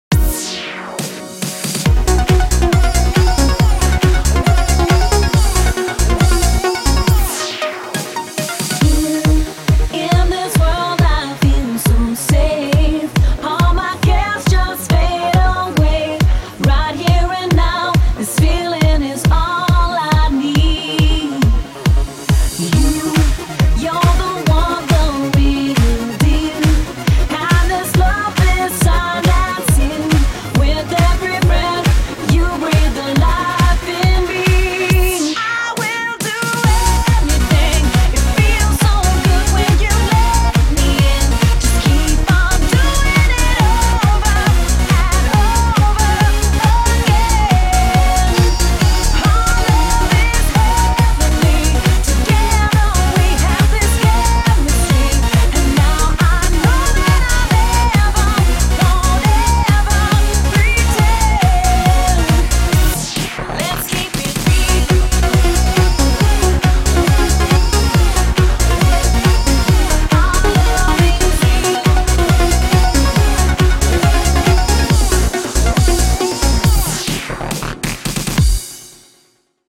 BPM138
Audio QualityCut From Video